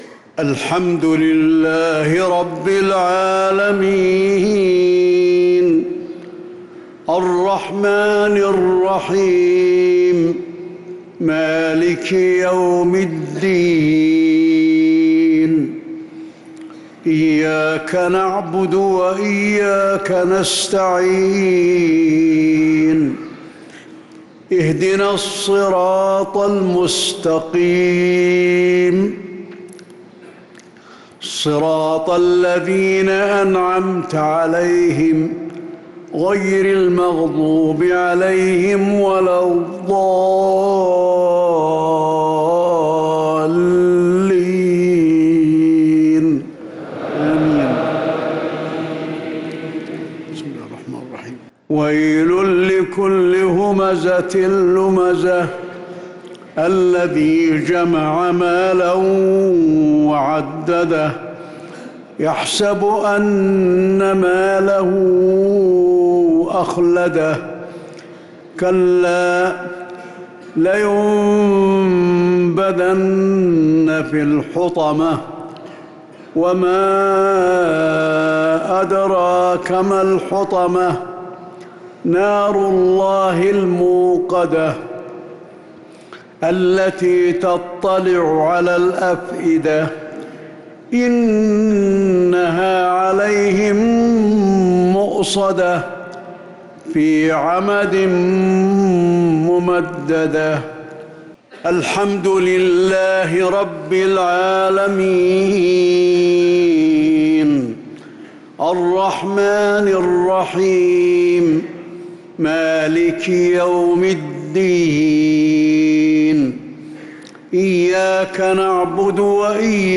مغرب الثلاثاء 4-9-1446هـ سورتي الهمزة و الكافرون كاملة | Maghrib prayer Surat al-Humazah & al-Kafirun 4-3-2025 > 1446 🕌 > الفروض - تلاوات الحرمين